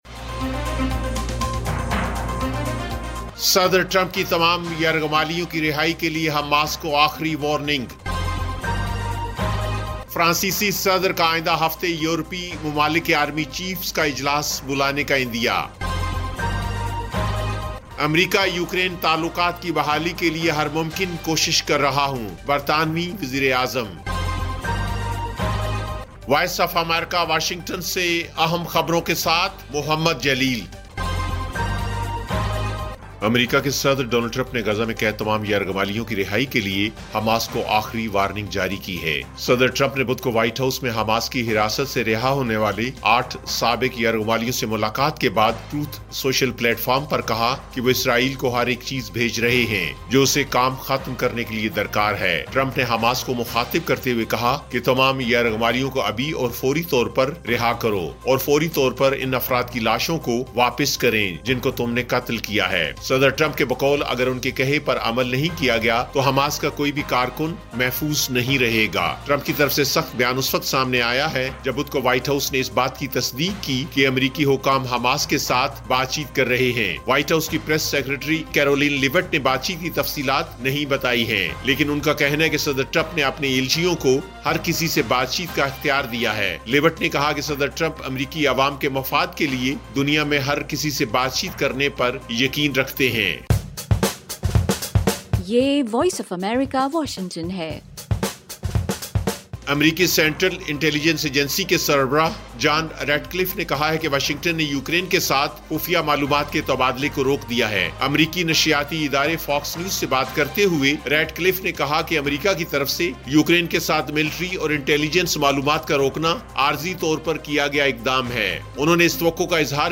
ایف ایم ریڈیو نیوز بلیٹن: شام 6 بجے